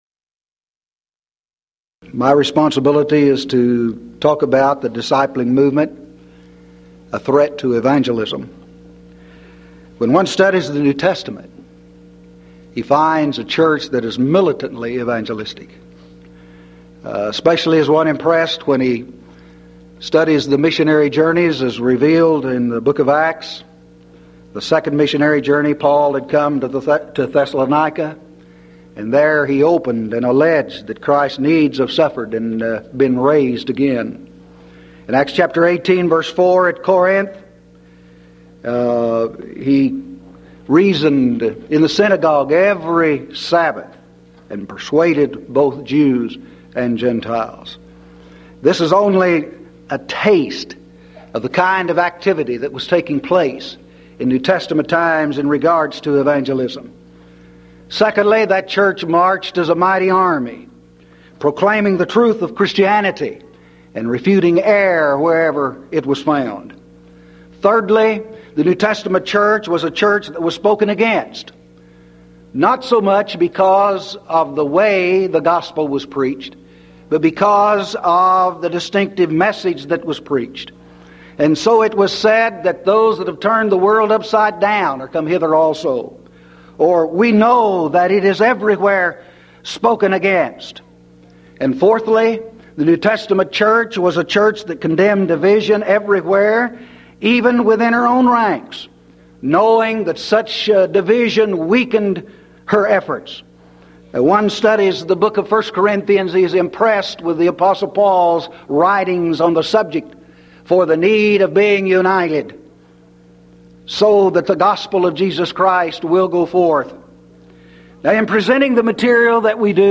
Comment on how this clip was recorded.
Event: 1994 Mid-West Lectures